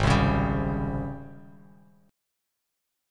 AbM11 chord